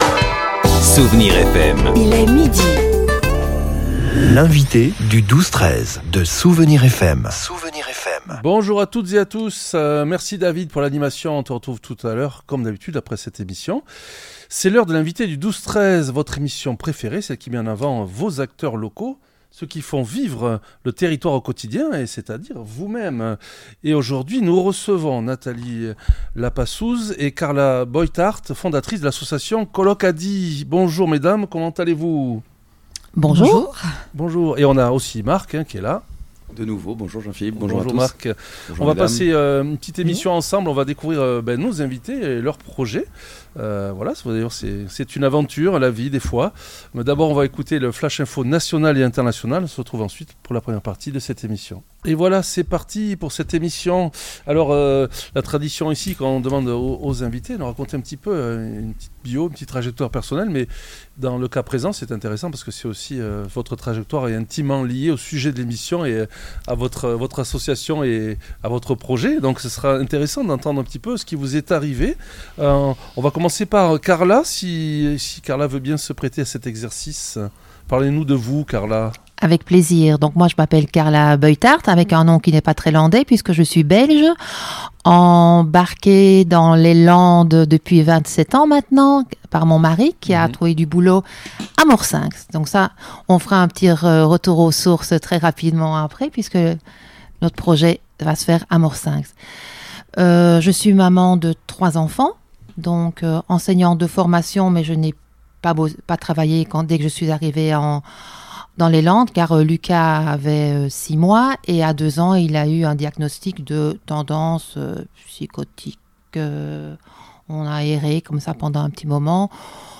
L'entretien a permis de détailler le montage audacieux de ce projet, soutenu par XL Habitat et la municipalité de Morcenx-la-Nouvelle.